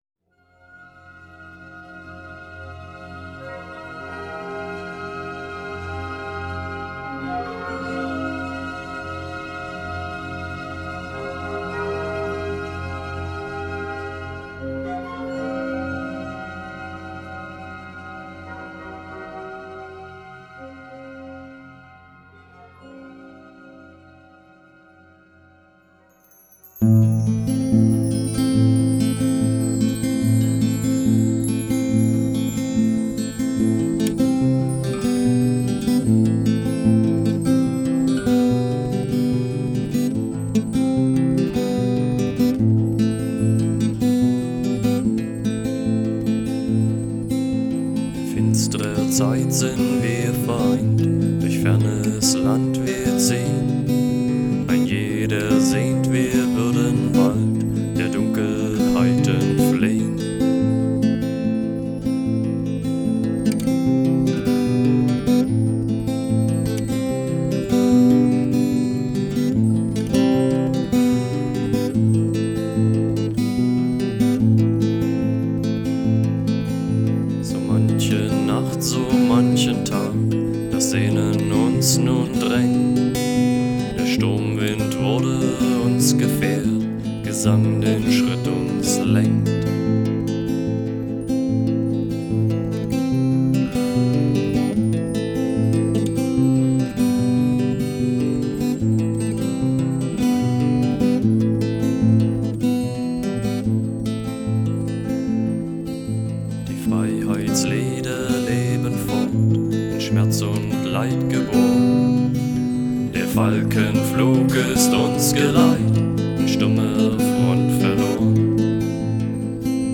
Стиль: Dark Folk